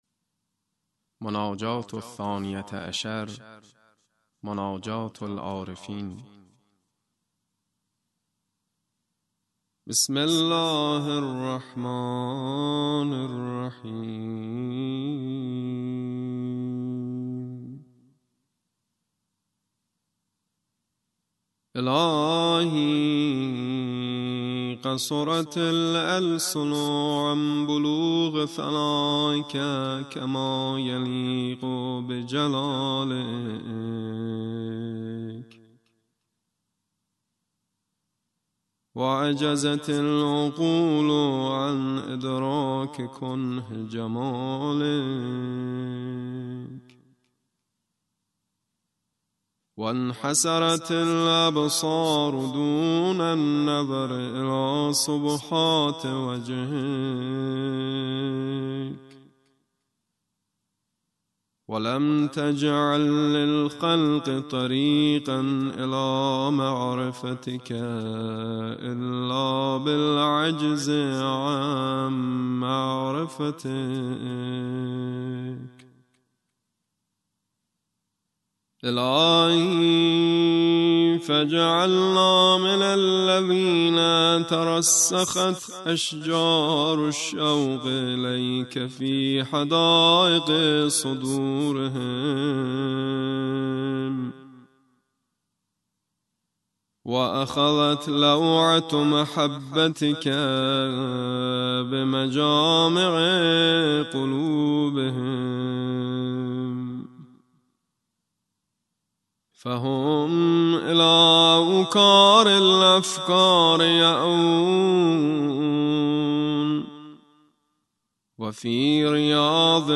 2152_Monajat-e-alarefin.mp3